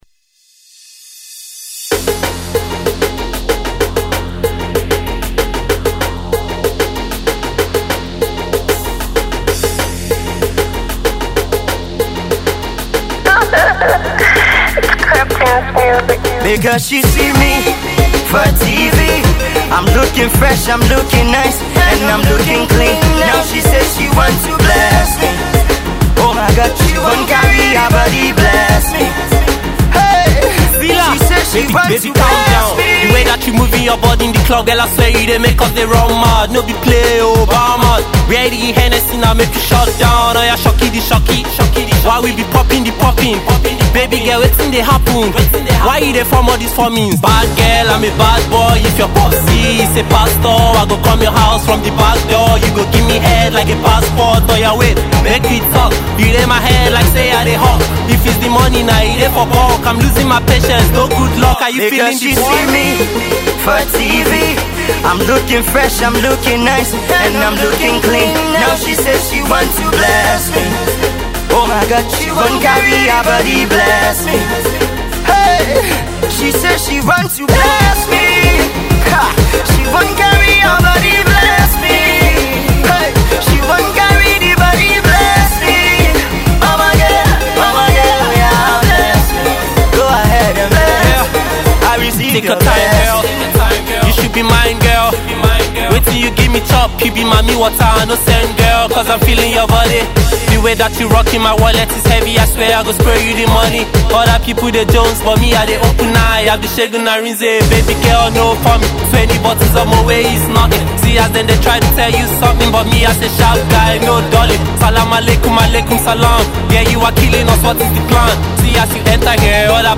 Pop Rap